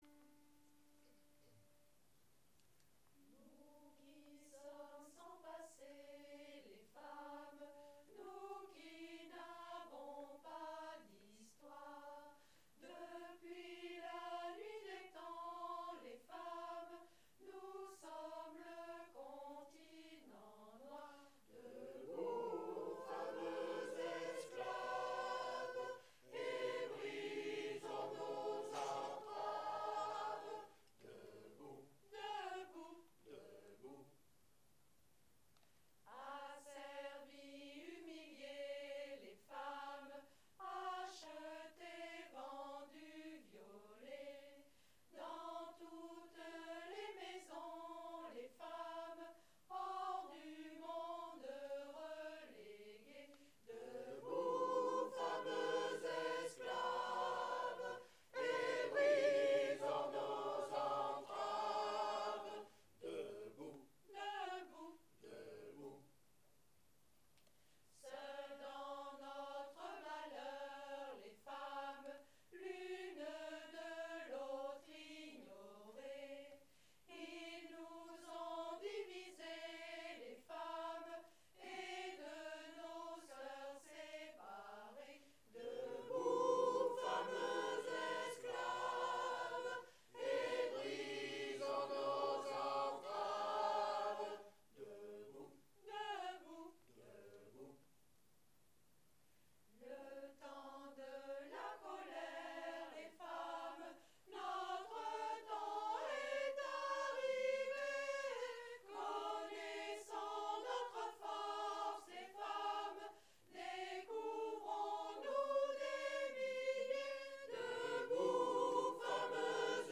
notre interprétation